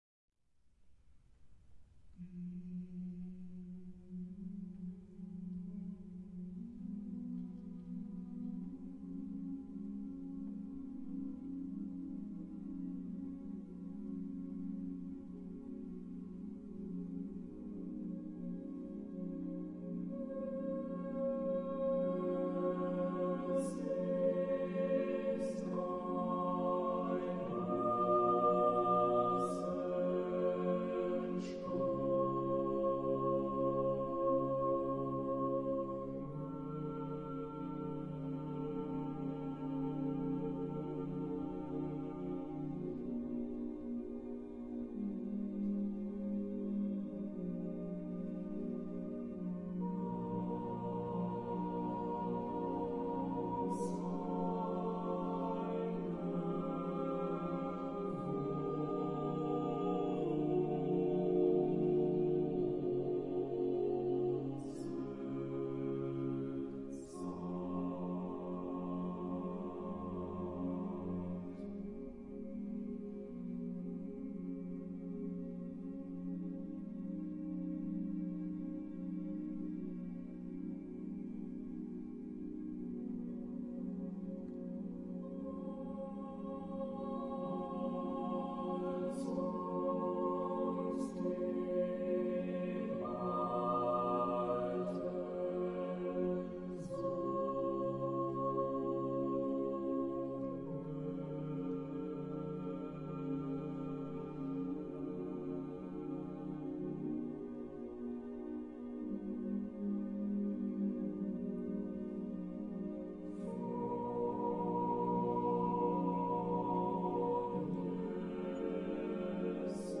EsIstEinRosEntsprungen_DresdnerKammerchor.mp3